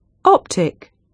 optisk [ˈɔptɪsk]